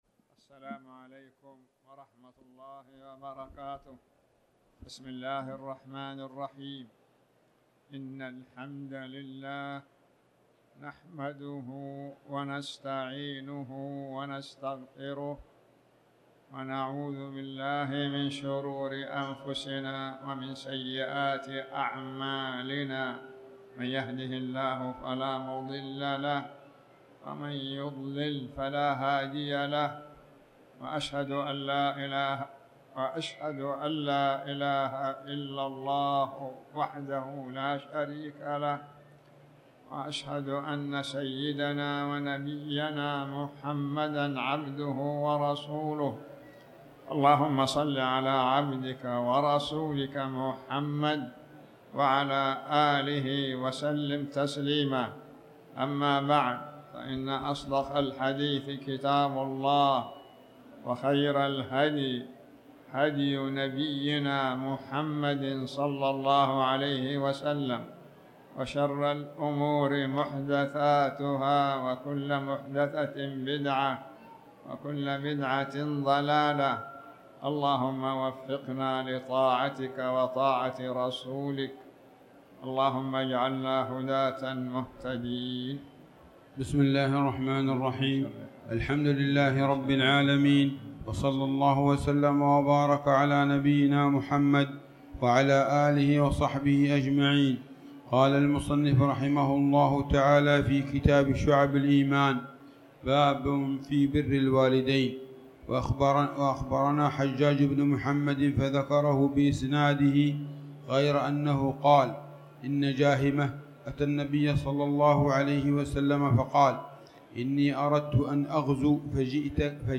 تاريخ النشر ١٨ شعبان ١٤٣٩ هـ المكان: المسجد الحرام الشيخ